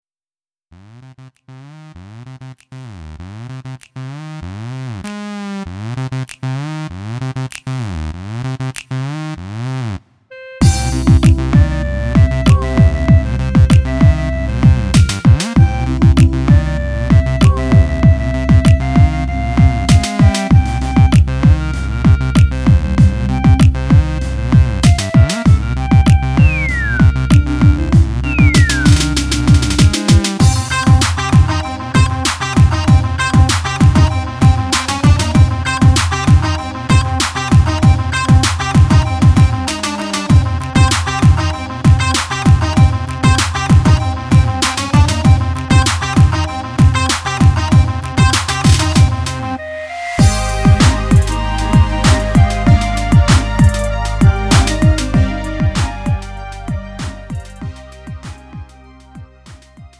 음정 원키 3:13
장르 구분 Lite MR